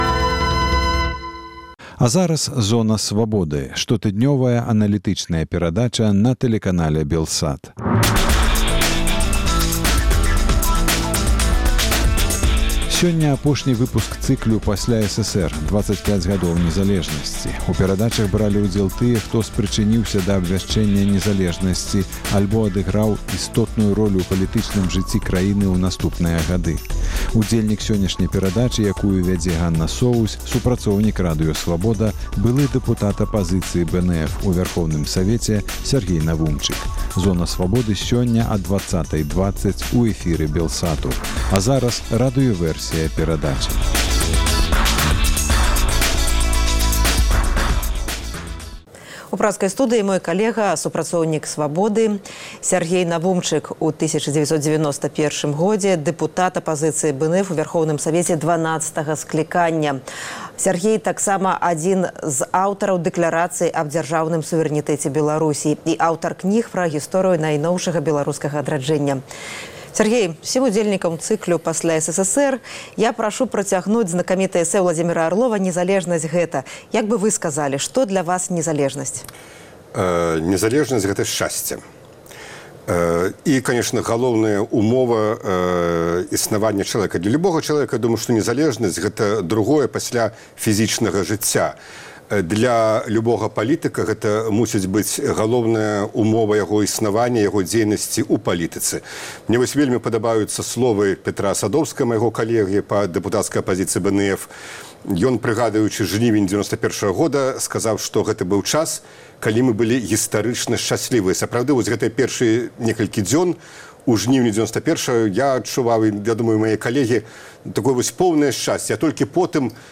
У перадачах бралі ўдзел тыя, хто спрычыніўся да абвяшчэньня незалежнасьці альбо адыграў істотную ролю ў палітычным жыцьці краіны ў наступныя гады. Удзельнік сёньняшняй перадачы – супрацоўнік Радыё Свабода, былы дэпутат Апазыцыі БНФ у Вярхоўным Савеце Сяргей Навумчык.